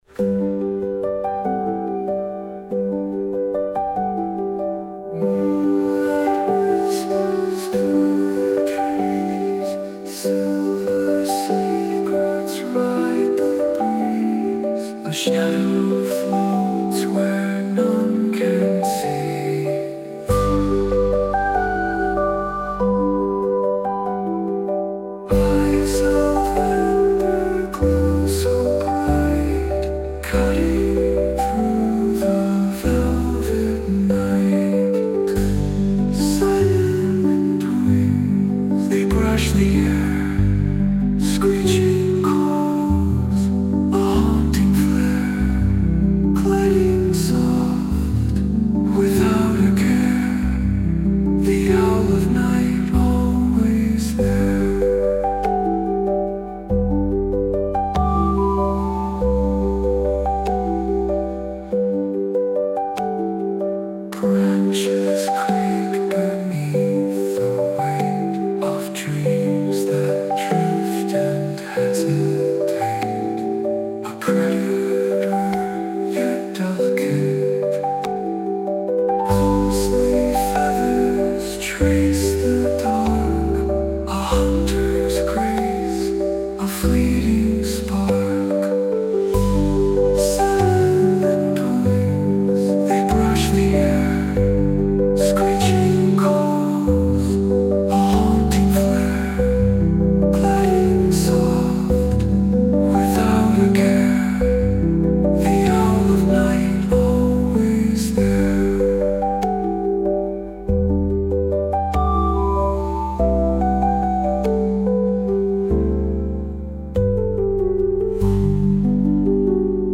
Six songs inspired by our backyard owls and created with artificial intelligence.